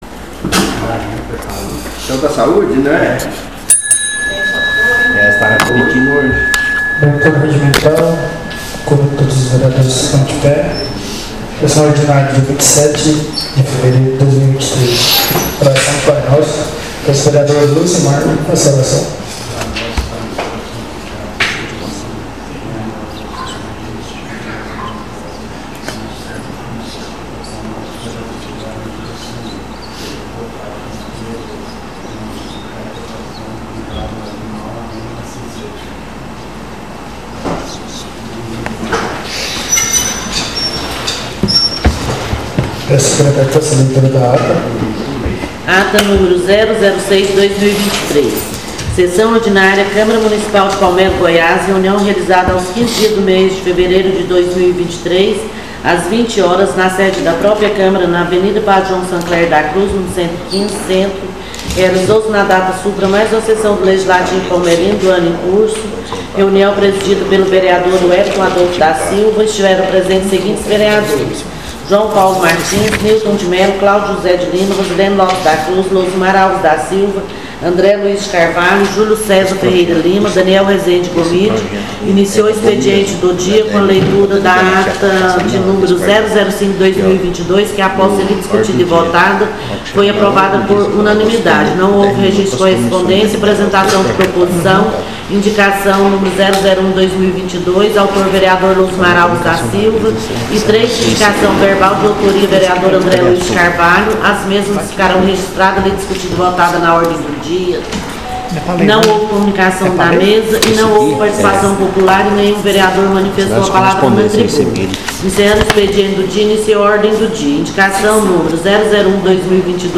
SESSÃO ORDINÁRIA DIA 27/02/2023